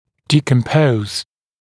[ˌdiːkəm’pəuz][ˌди:кэм’поуз]разлагать на составные части; гнить, портиться, разлагаться